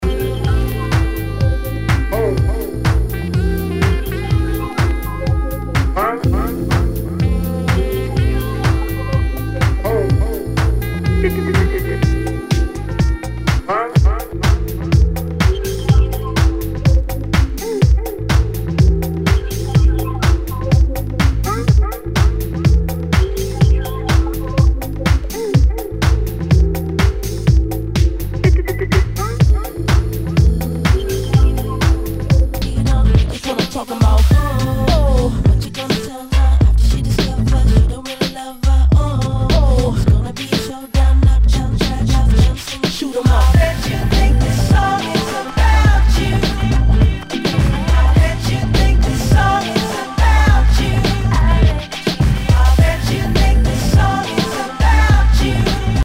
HOUSE/TECHNO/ELECTRO
ナイス！ディープ・ハウス / R&B！
全体にチリノイズが入ります